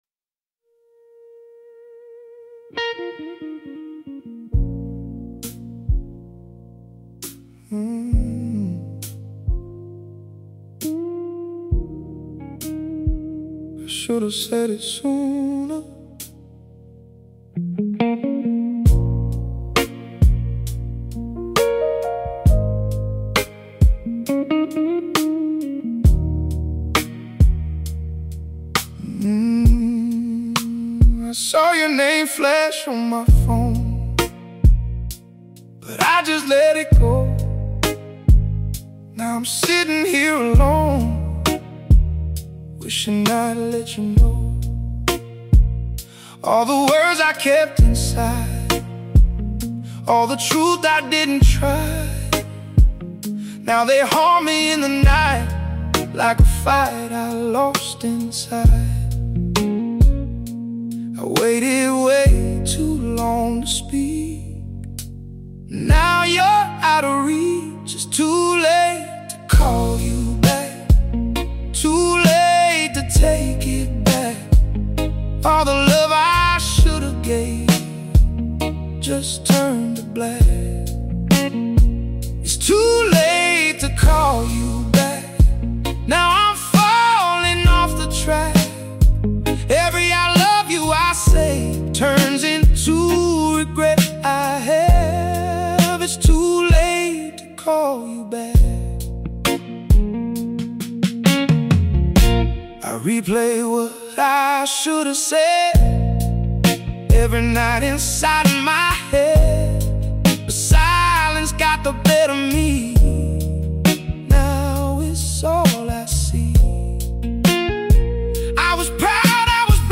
There is a quiet heaviness in how this song unfolds.
As it plays, the mood deepens.